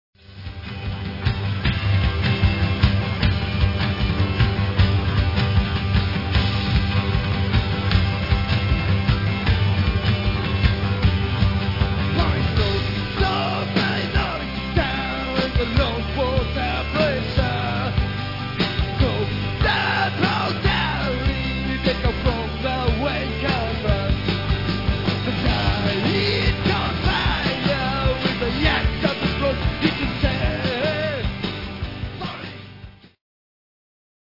大阪パンクの最硬派